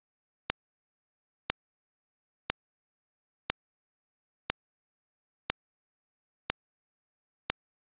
Wenn wir aber den Unterschied zwischen zwei und einem Sample Abstand als Loop hören, kann unser Ohr sogar hier noch differenzieren.
1sample-4x.wav